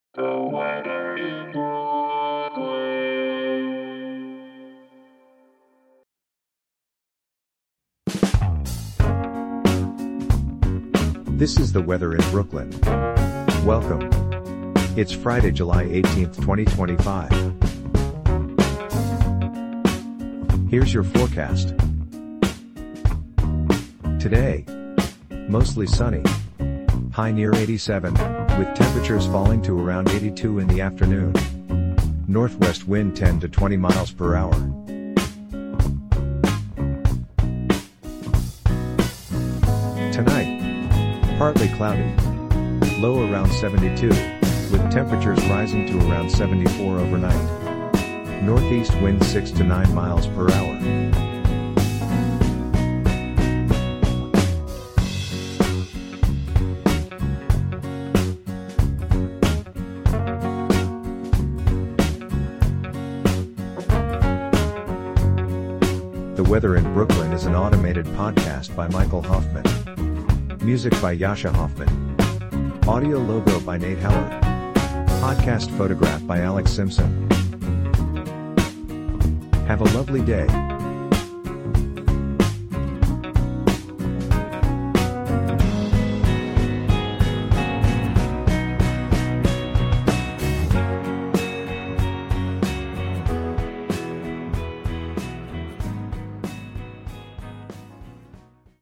and is generated automatically.